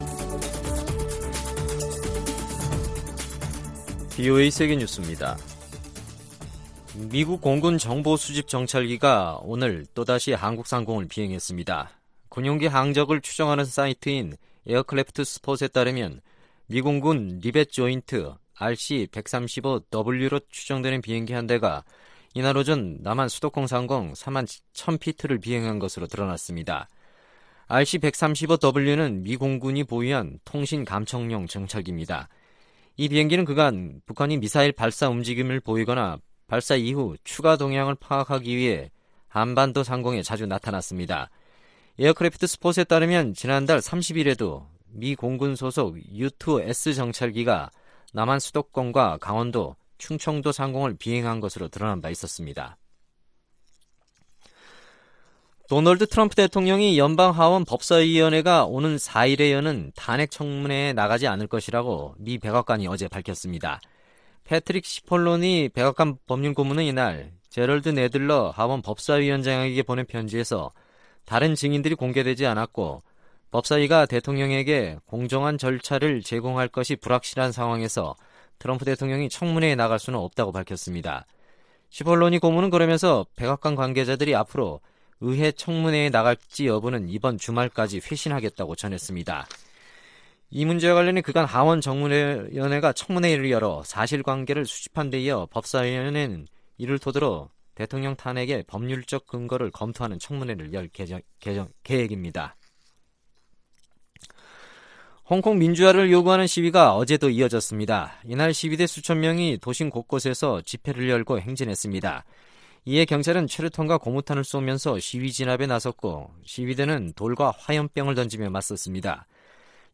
VOA 한국어 간판 뉴스 프로그램 '뉴스 투데이', 2019년 12월 2일 2부 방송입니다. 러시아와 중국을 잇는 대규모 천연가스관이 2일 개통됐습니다. 추수감사절 다음날인 ‘블랙프라이데이’ 온라인 쇼핑 액수가 올해 사상 최고치를 기록한 것으로 나타났습니다.